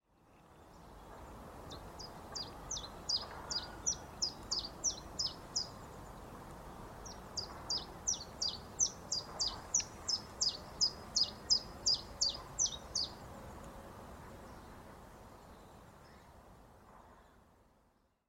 XC704028-Common-Chiffchaff-Phylloscopus-collybita